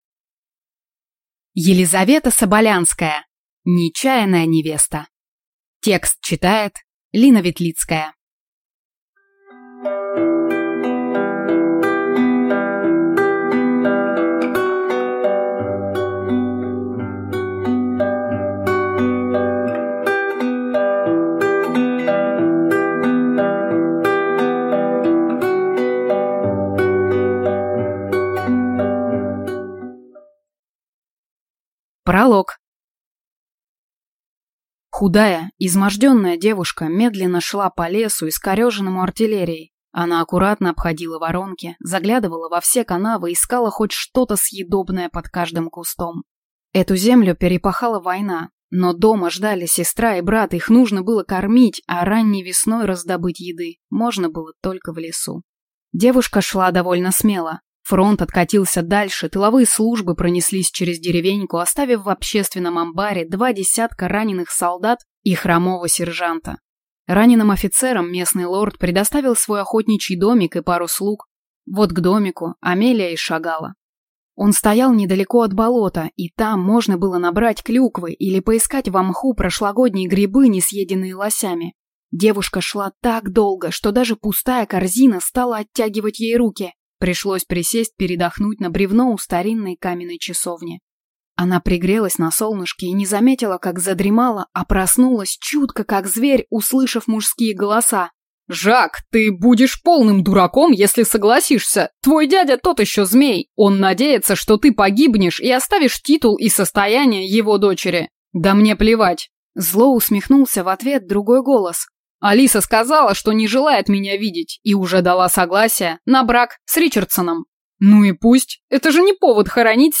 Аудиокнига Нечаянная невеста | Библиотека аудиокниг